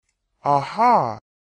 Звуки человека, аха
• Качество: высокое
Немного другая интонация ага